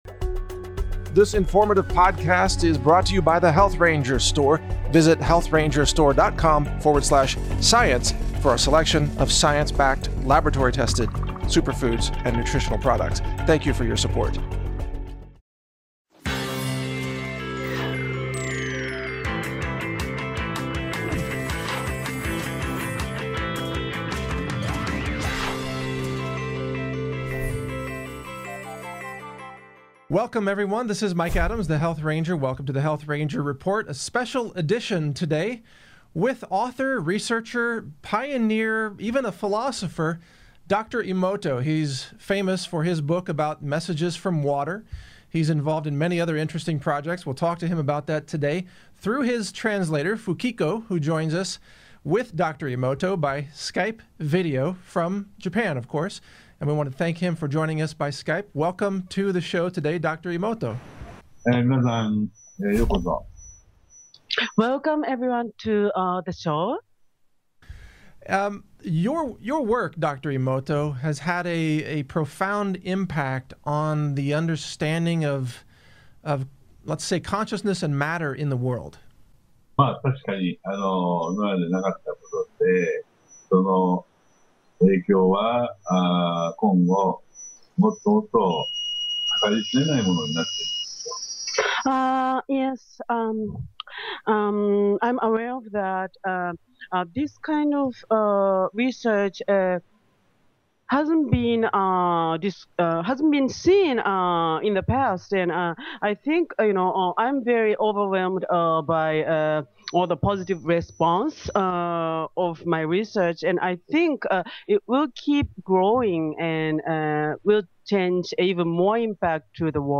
Dr. Masaru Emoto, author of Messages From Water, interviewed by Mike Adams, the Health Ranger - Natural News Radio
HRR-dr-masaru-emoto-author-of-messages-from-water-interviewed-by-mike-adams-the-health-ranger.mp3